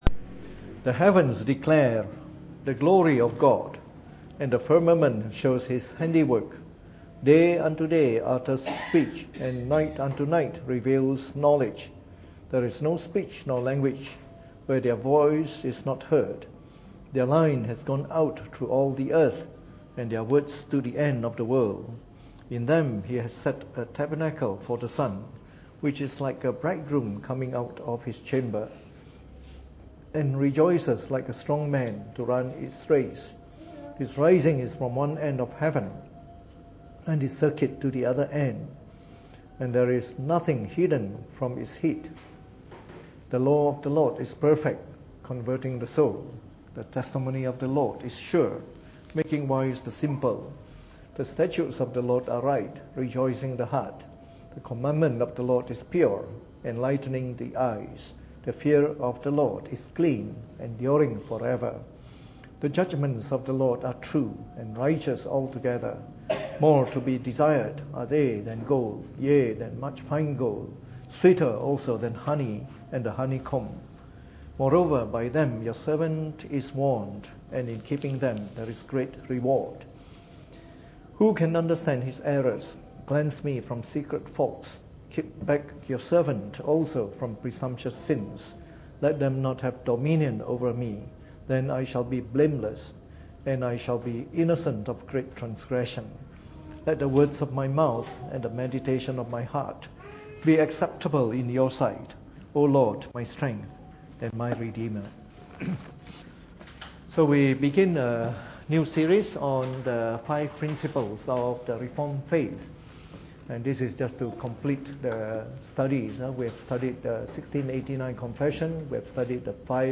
Preached on the 12th of October 2016 during the Bible Study, from our new series on the Five Principles of the Reformed Faith.